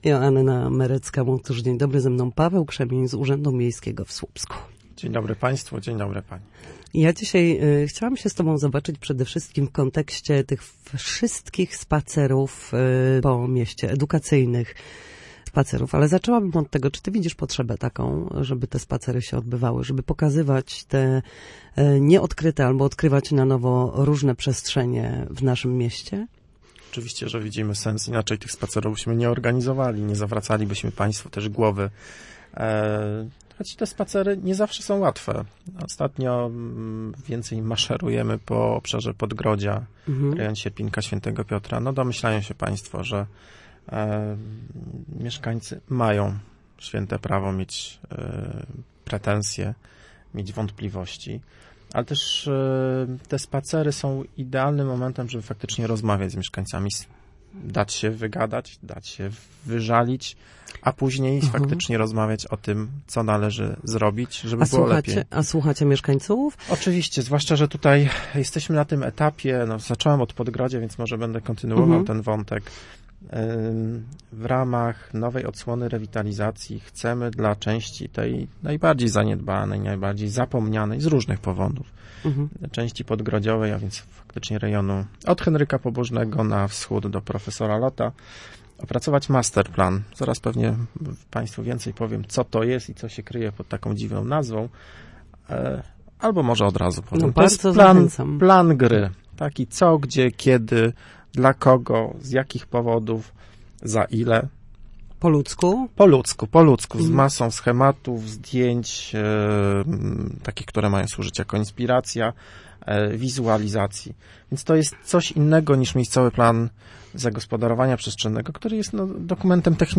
W Słupskim Studiu Radia Gdańsk